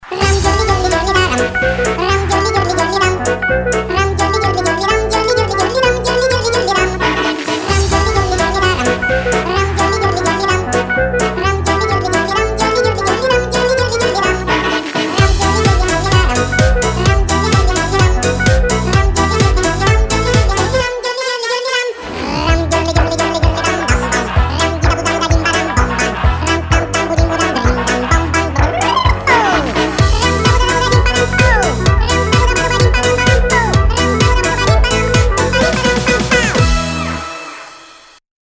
• Качество: 320, Stereo
позитивные
веселые
без слов
забавный голос
смешные
детский хор